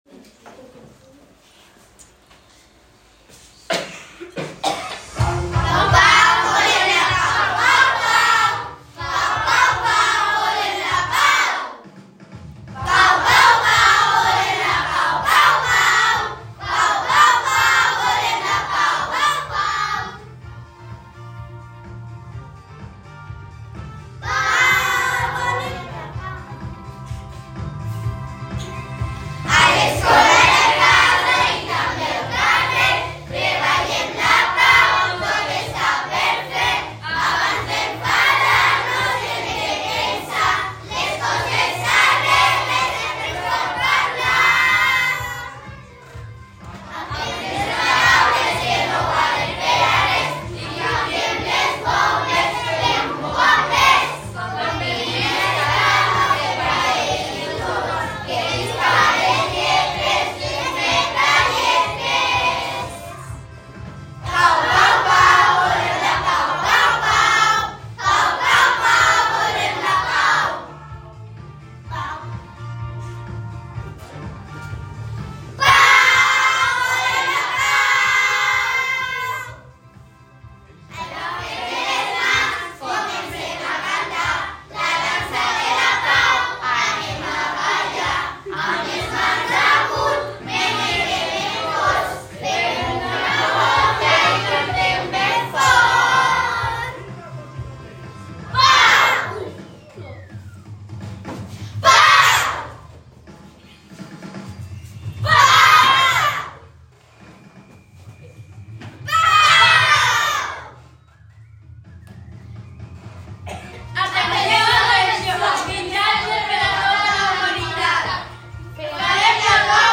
Els i les alumnes que participen a la coral de l’escola van interpretar el dia 30 de gener, dia de la No-violència i la pau la cançó Volem la Pau. La setmana passada a la sessió del dilluns la vam enregistrar per poder tenir-la gravada.